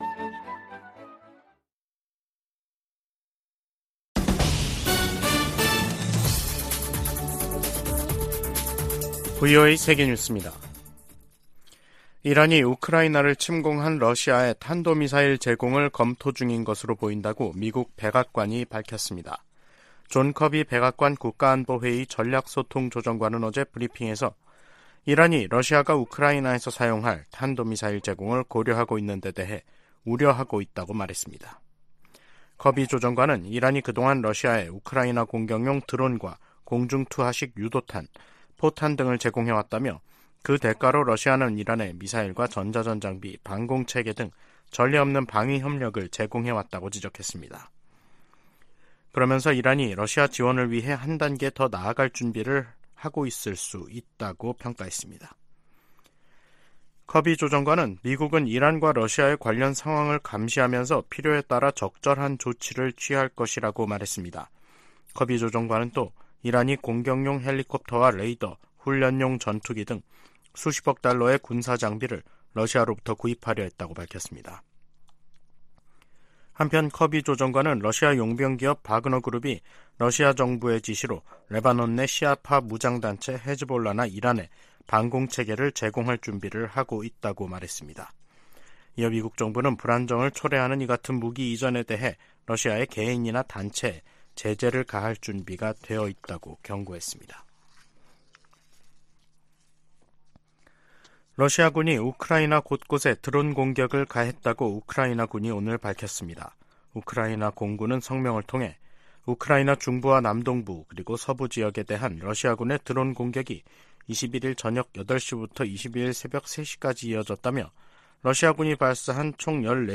VOA 한국어 간판 뉴스 프로그램 '뉴스 투데이', 2023년 11월 22일 2부 방송입니다. 북한이 군사정찰위성 발사 궤도 진입 성공을 발표하자 미국은 강하게 규탄하고 동맹 방어에 필요한 모든 조치를 취하겠다고 밝혔습니다. 한국 정부는 9.19 남북 군사합의 일부 효력을 정지시켰습니다. 유엔과 유럽연합(EU) 등도 북한의 3차 군사정찰위성 발사가 안보리 결의 위반이라며 강력하게 규탄했습니다.